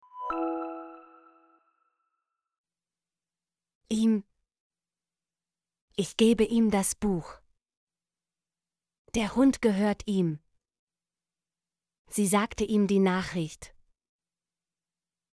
ihm - Example Sentences & Pronunciation, German Frequency List